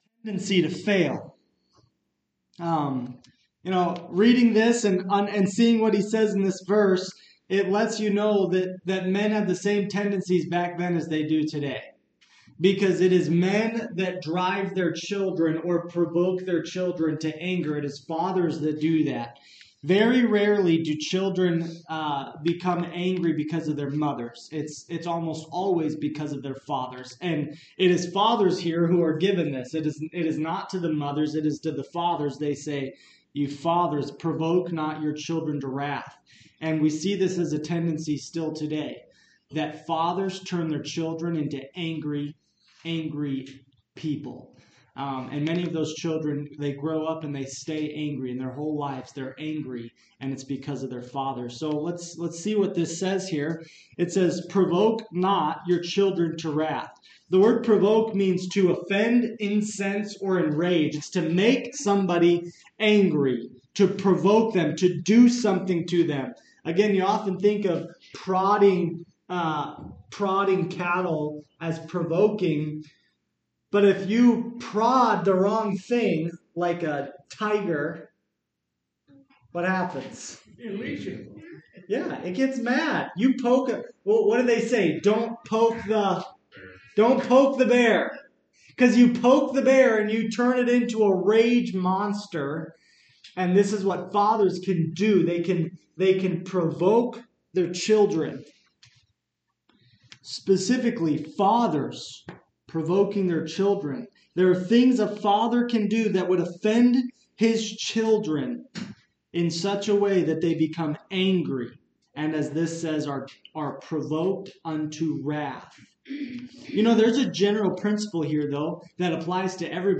Preacher
Service Type: Wednesday Evening Topics: Fathers , Parenting